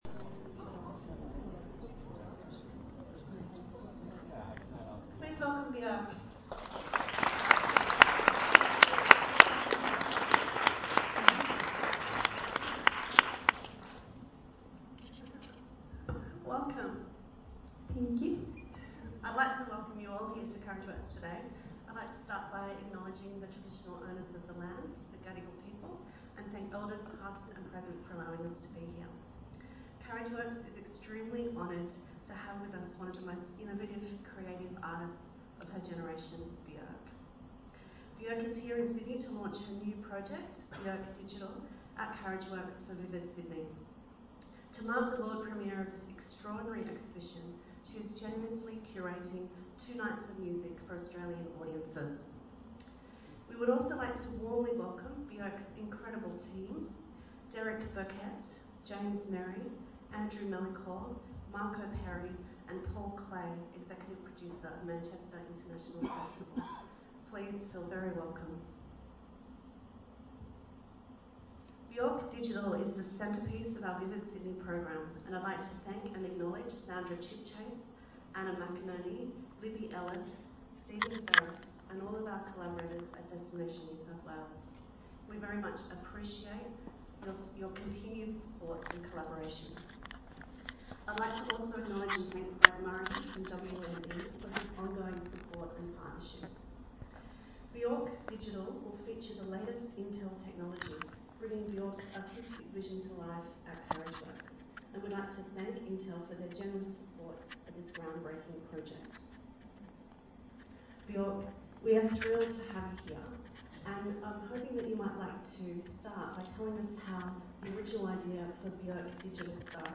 The Q& A with Bjork at Carriageworks today:
dvt_b010-bjork-q-a.mp3